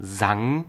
Ääntäminen
Ääntäminen Tuntematon aksentti: IPA: /zaŋ/ Haettu sana löytyi näillä lähdekielillä: saksa Käännöksiä ei löytynyt valitulle kohdekielelle. Sang on sanan singen imperfekti.